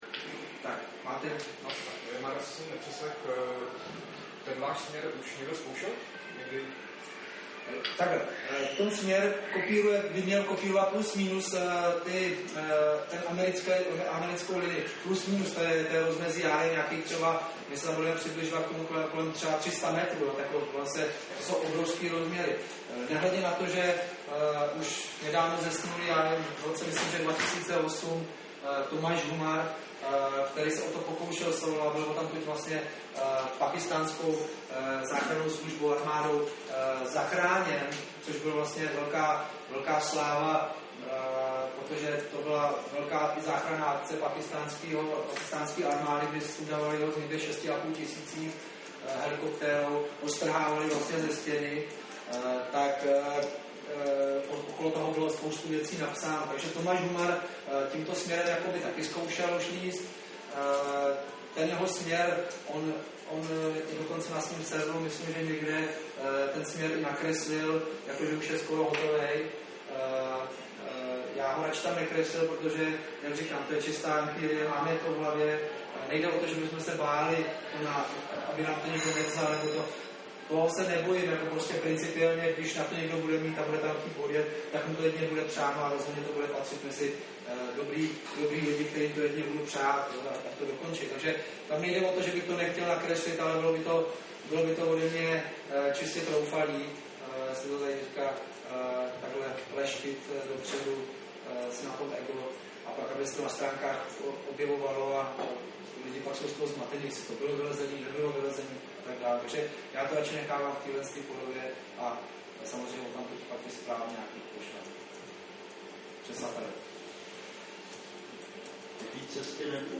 Konference se zúčastnili hlavně zástupci odborných médií.